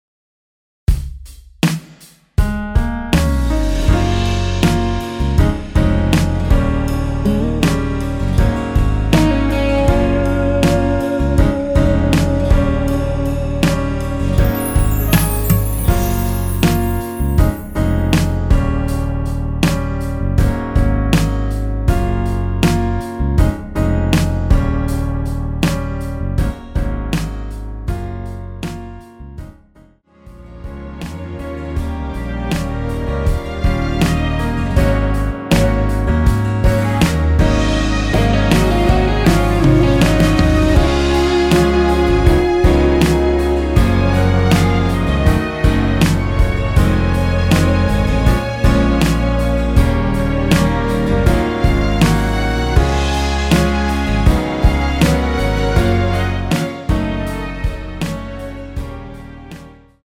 엔딩이 페이드 아웃이라 라이브 하시기 좋게 엔딩을 만들어 놓았습니다.
Db
앞부분30초, 뒷부분30초씩 편집해서 올려 드리고 있습니다.
중간에 음이 끈어지고 다시 나오는 이유는